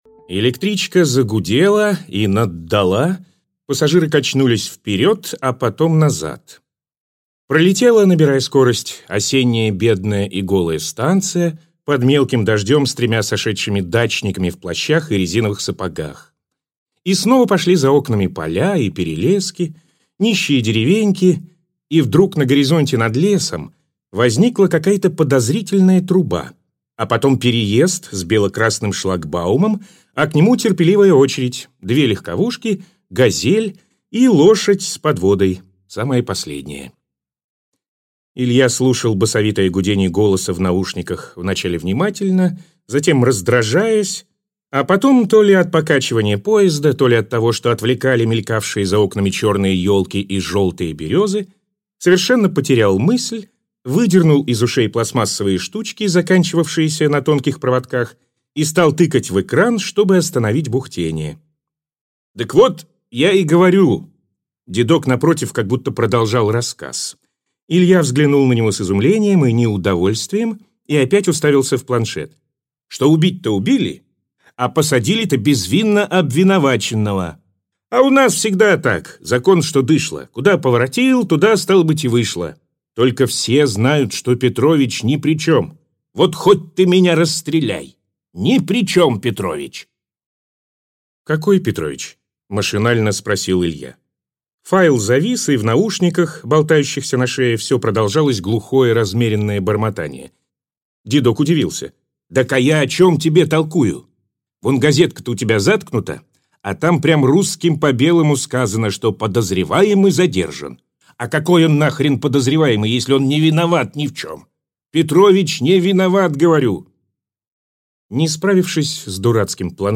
Аудиокнига Селфи с судьбой - купить, скачать и слушать онлайн | КнигоПоиск